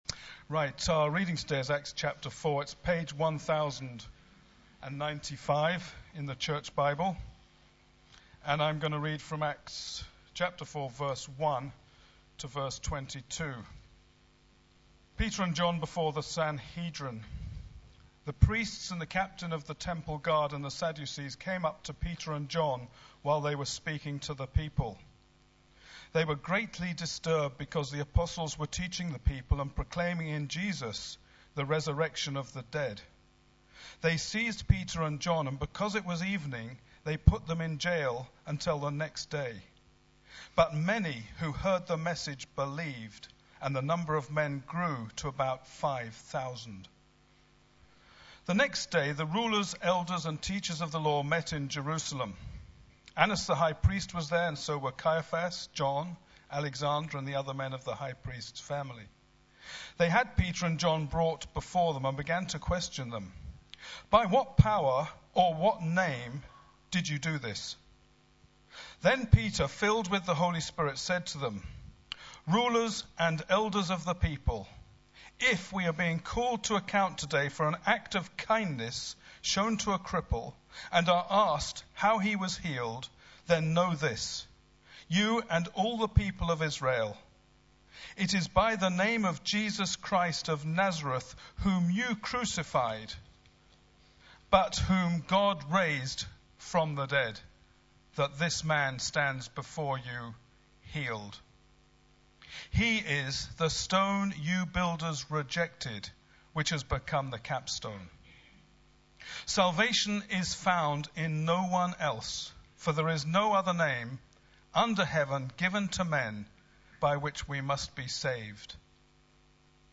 Sunday Service
The Uniqueness of Jesus Christ Theme: Christ as Saviour Sermon